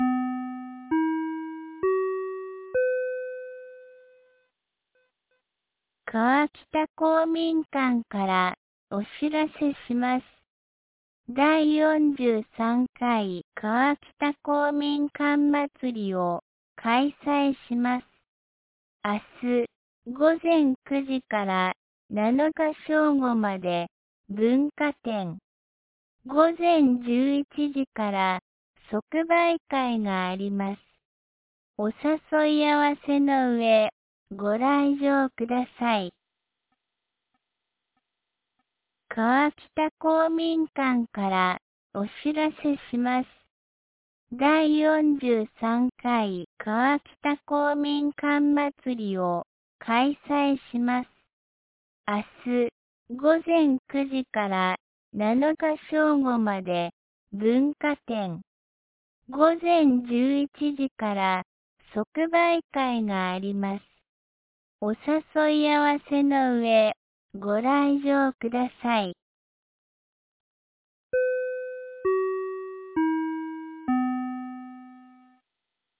2025年12月05日 17時11分に、安芸市より全地区へ放送がありました。